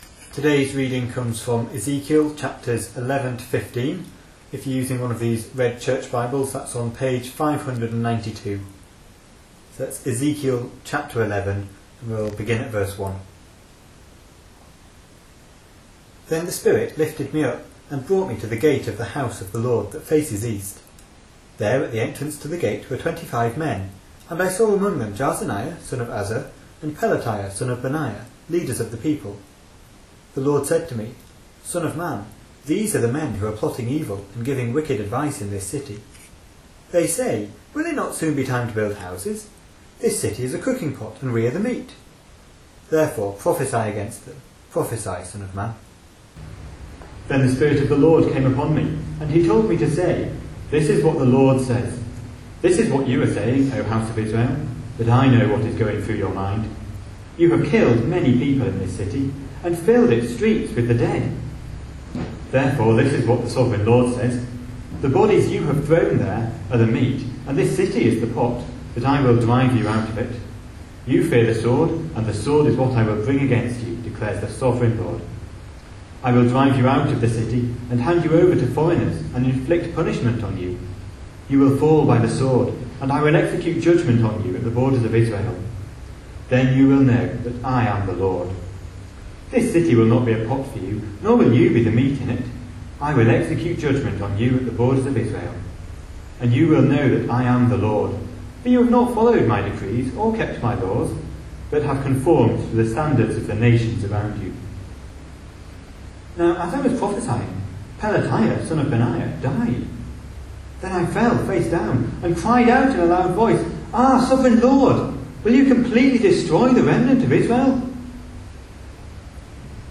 A sermon preached on 22nd June, 2014, as part of our Ezekiel series.